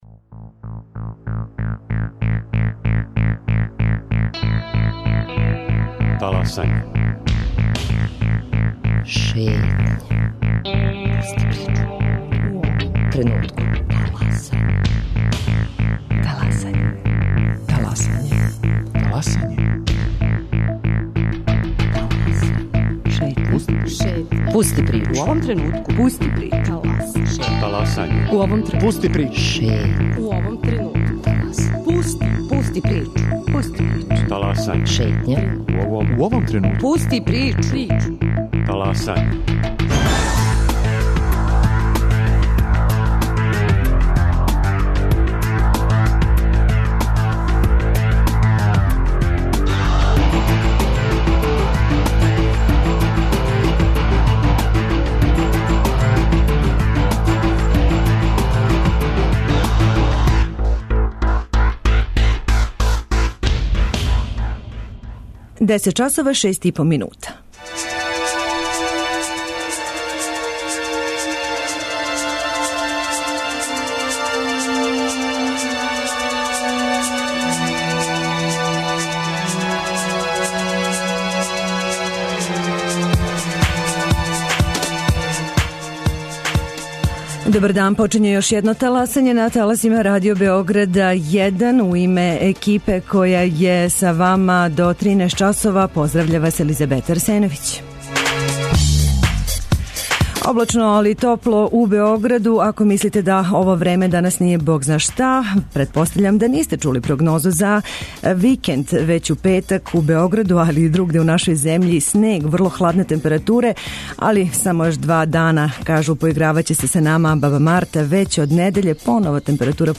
Радио Београд 1, 10.05